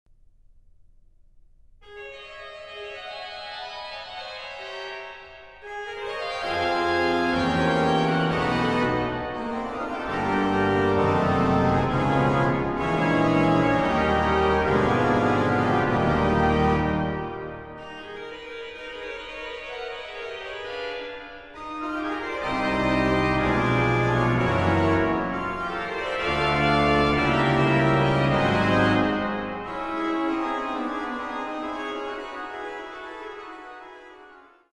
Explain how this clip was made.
in Middelburg, The Netherlands